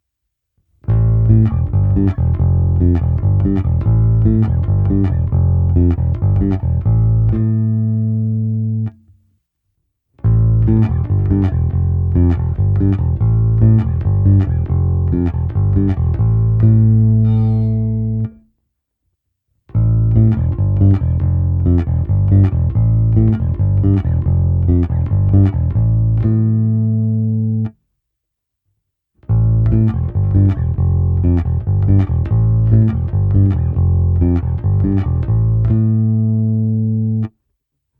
Postupně je v ukázkách čistý zvuk baskytary bez kompresoru, pak kompresor s režimy v pořadí NORMAL, MB a nakonec TUBESIM.
To samé, ale se simulací aparátu, kdy jsem použil impuls boxu Ampeg 8x10".
Ukázka prsty + IR